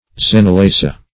Search Result for " xenelasia" : The Collaborative International Dictionary of English v.0.48: Xenelasia \Xen`e*la"si*a\, n. [NL., from Gr. xenhlasi`a expulsion of strangers.]